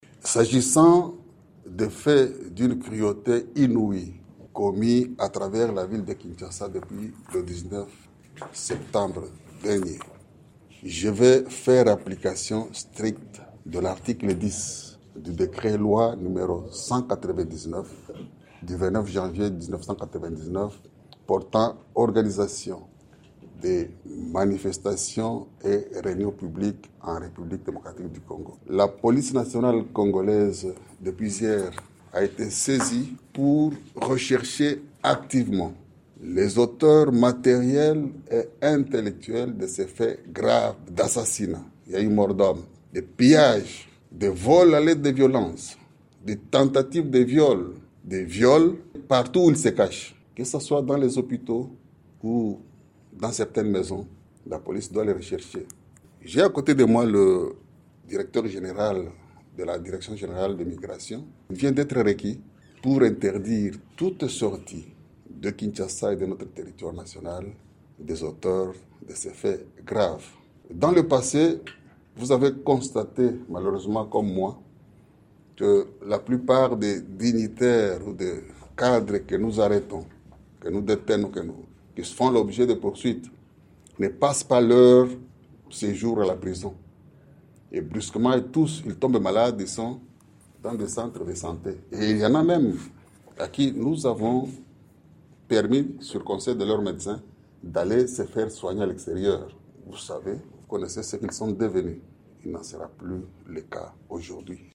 RDC : le PGR annonce des mandats et l’interdiction contre les opposants-au micro de TopCongo